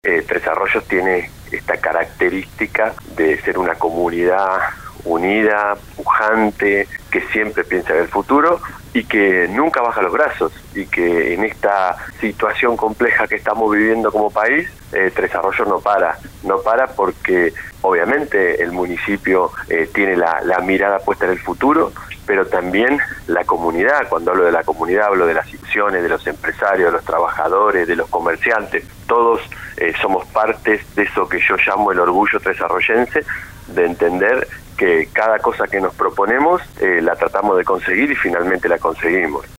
(Audio)Pablo Garate brindó unas palabras en un nuevo Aniversario de Tres Arroyos
En el Aniversario 142 de Tres Arroyos, el Intendente Pablo Garate habló con LU 24, analizó el presente de nuestra localidad y manifestó: “hace unos días vengo diciendo que, a pesar del contexto tan difícil, Tres Arroyos tiene la característica de ser una comunidad unida y pujante.